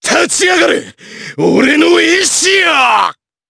Chase-Vox_Skill6_jp.wav